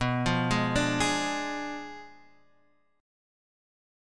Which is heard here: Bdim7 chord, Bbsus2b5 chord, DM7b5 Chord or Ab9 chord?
Bdim7 chord